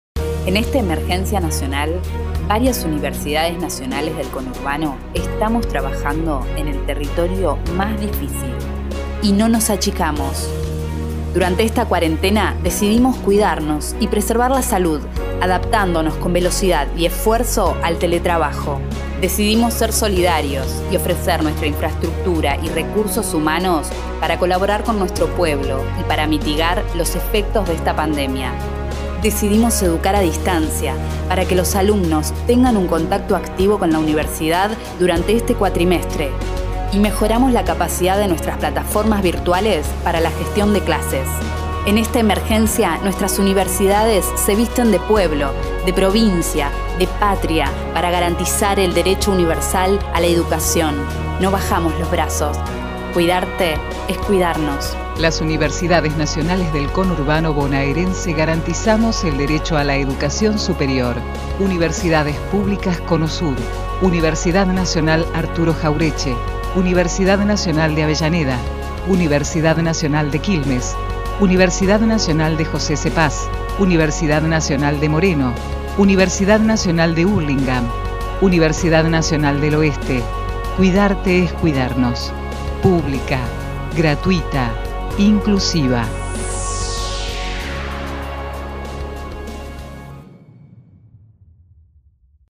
SPOT universidades publicas.mp3